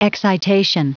Prononciation du mot excitation en anglais (fichier audio)